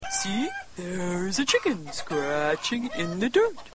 chicken scratching